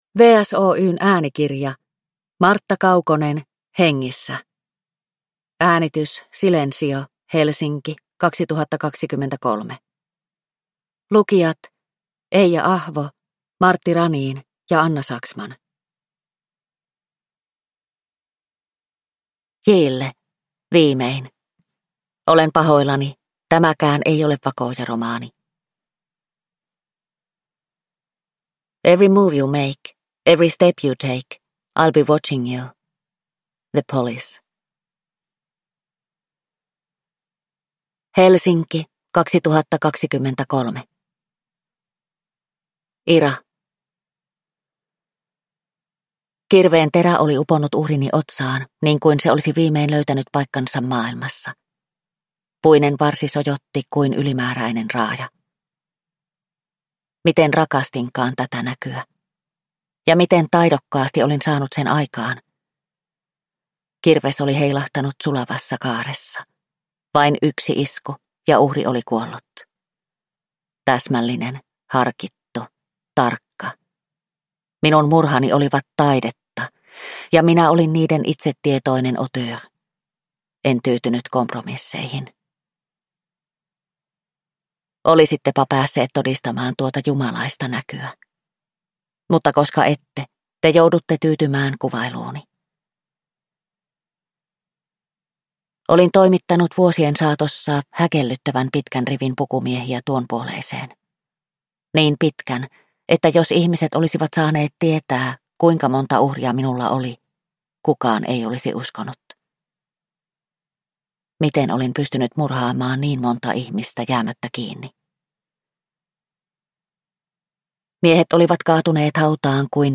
Hengissä – Ljudbok